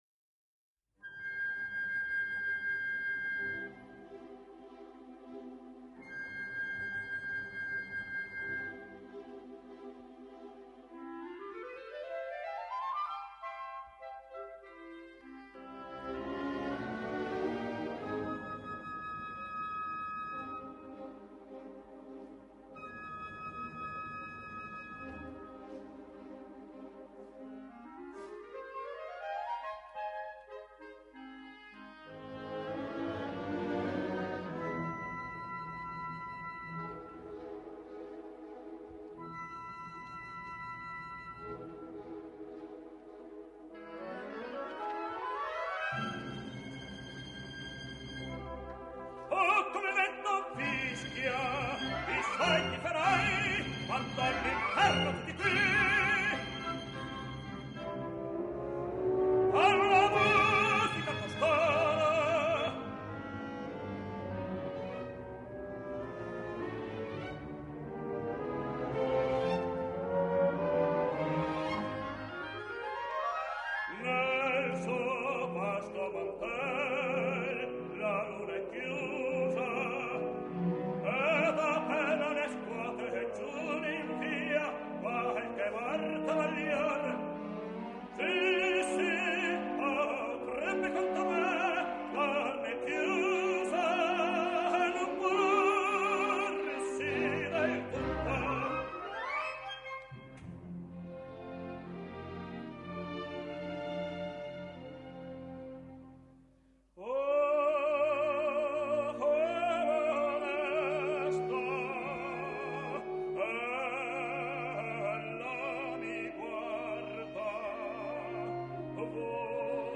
{Verismo} — Scapigliatura
Guglielmo Ratcliff [Tenor]